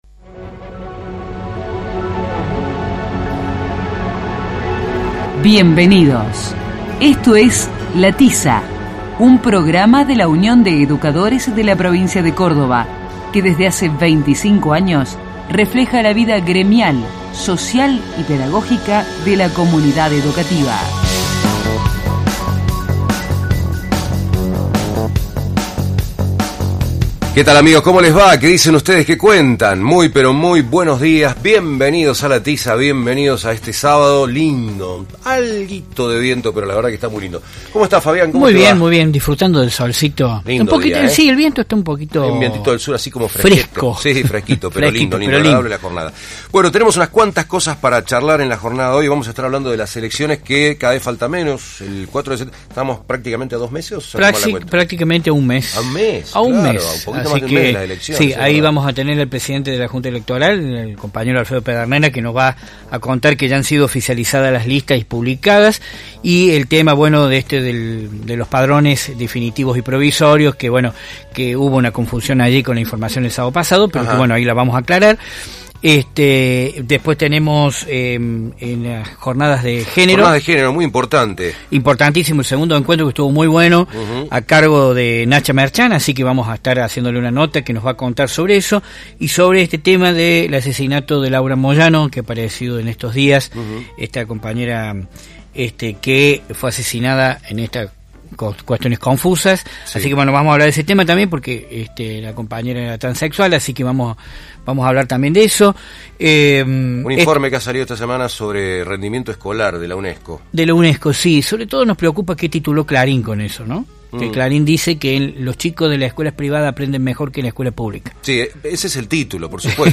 El Sindicato cuenta con el programa de radio "La Tiza", en el cual se reflejan las temáticas gremiales, educativas y pedagógicas que interesan a los y las docentes.
El programa se emite los sábados de 12 a 13 hs por radio Universidad (AM 580) y de 16 a 17 hs por Más que música (FM 102.3).